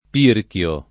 pirchio [ p & rk L o ]